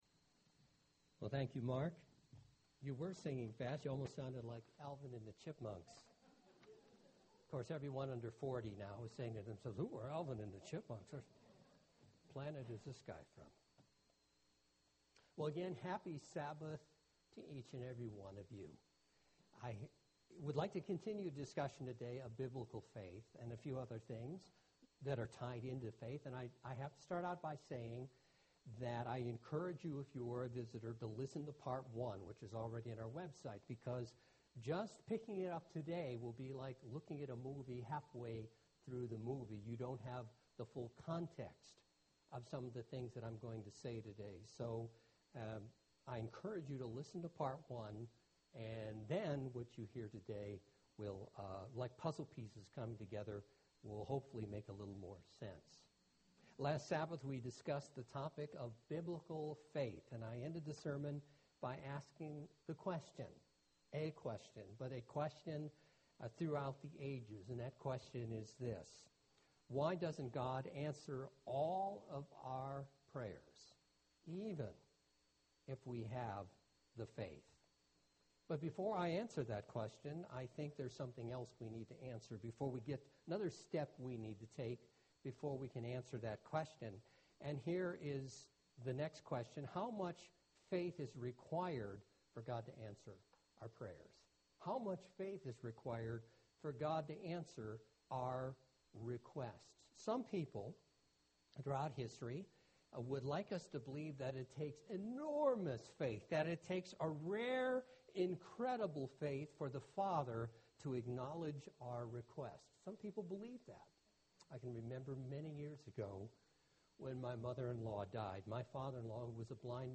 This is the second part of a multi-part sermon. In this Sermon we will focus on how much faith is required for God to answer our prayers. Let's explore what we can learn about how God answers prayers and what His answers are based upon.